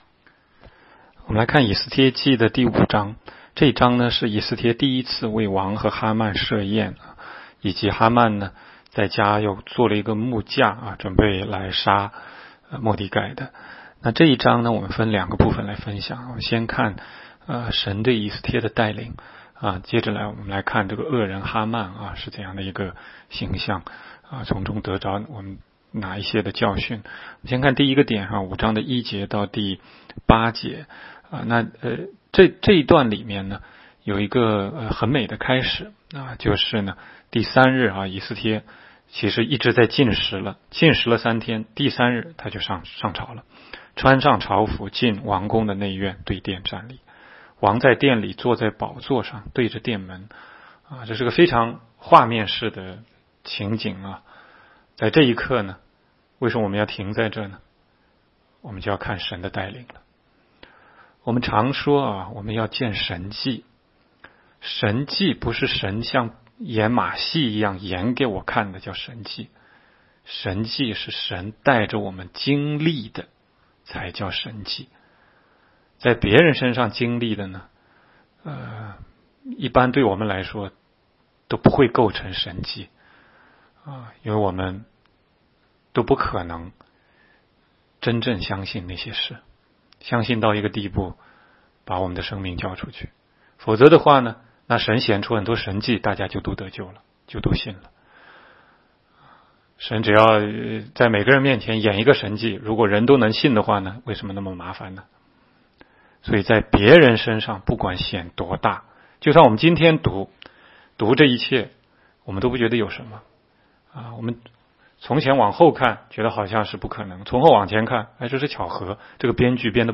16街讲道录音 - 每日读经-《以斯帖记》5章